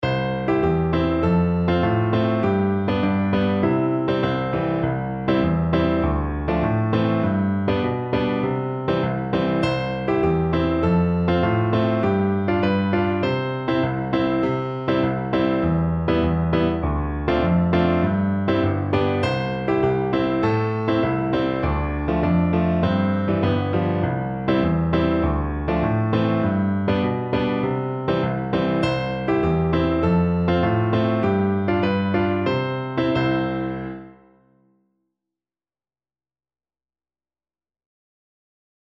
Flute
C major (Sounding Pitch) (View more C major Music for Flute )
Joyfully
2/4 (View more 2/4 Music)
Traditional (View more Traditional Flute Music)